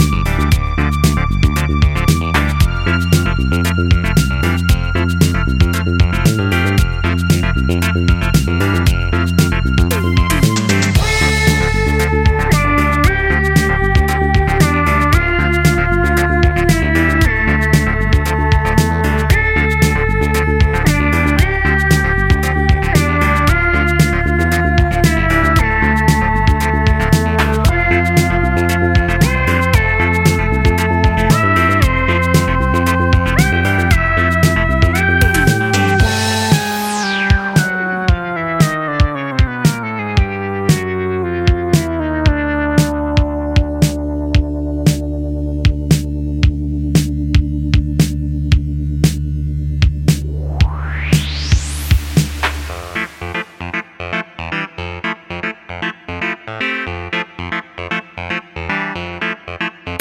ジャンル(スタイル) NU DISCO / JAZZ FUNK / BALEARICA